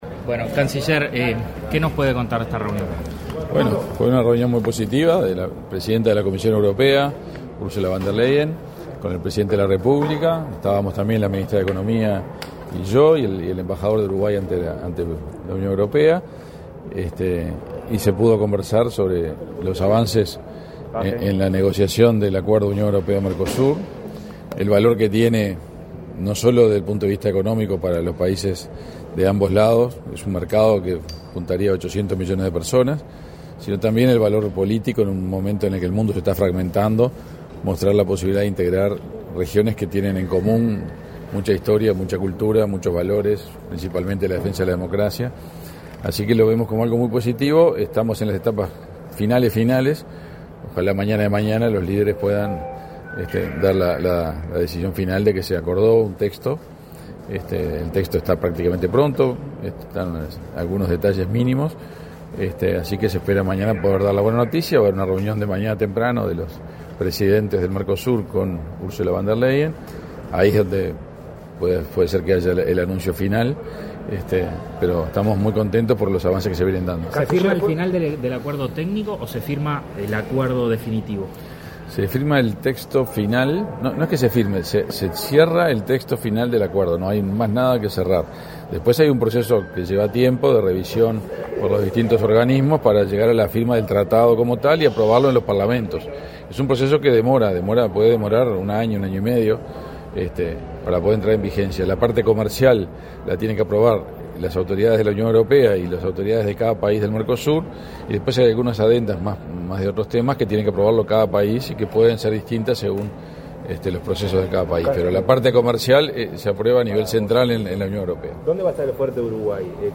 Declaraciones a la prensa del canciller de la República, Omar Paganini
Tras el encuentro, el canciller Omar Paganini realizó declaraciones a la prensa.